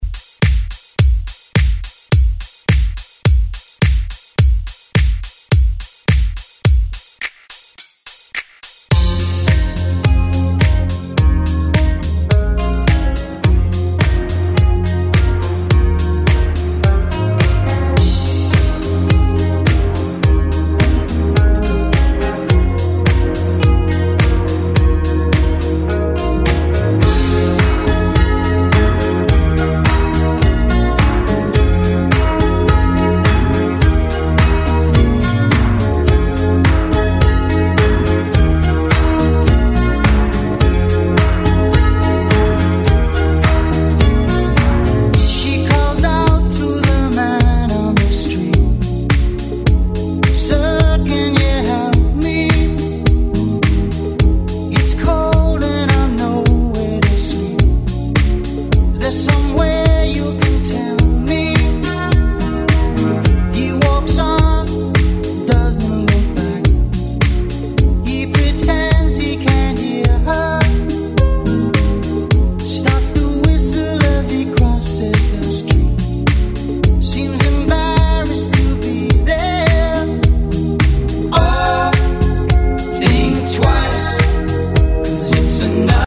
Genres: 80's , RE-DRUM
Clean BPM: 106 Time